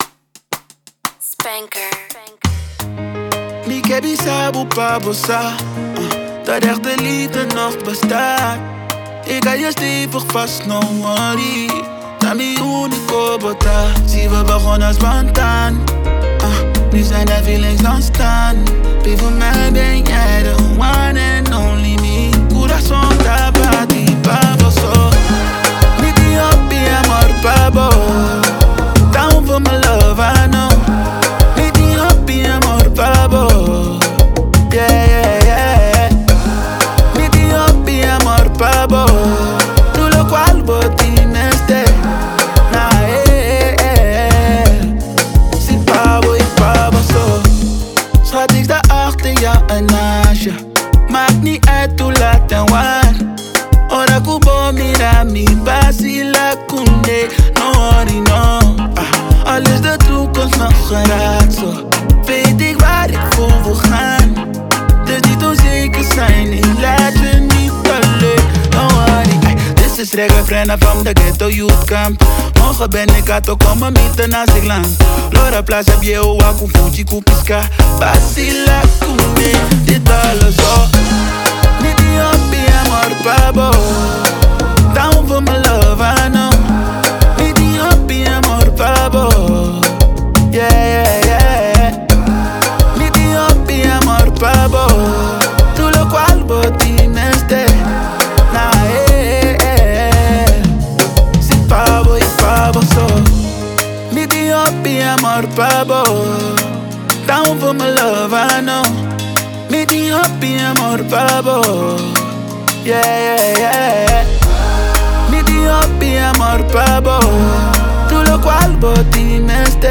ta un zouk ku aksèntnan Afro kantá na Papiamentu i Ulandes